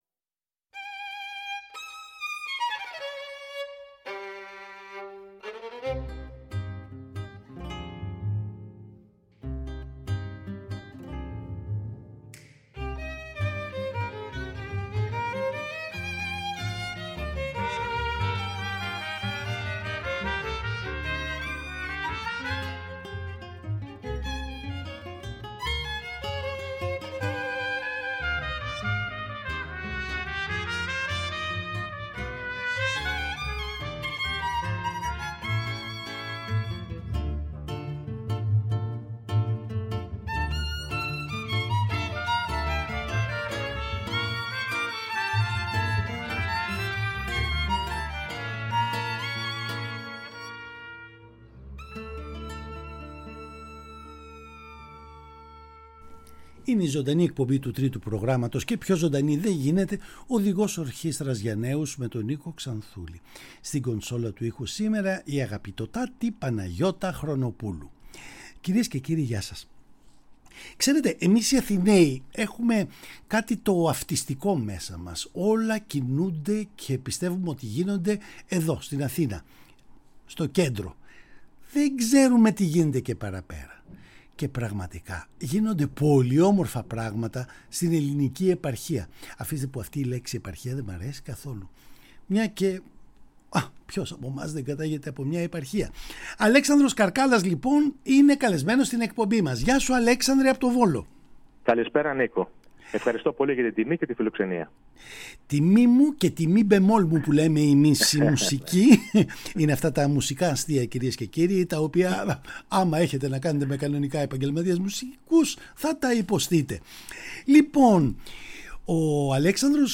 Τον προσκαλέσαμε να μιλήσουμε για τις όμορφες μουσικές του ιδέες και να ακούσουμε υπέροχες μουσικές του.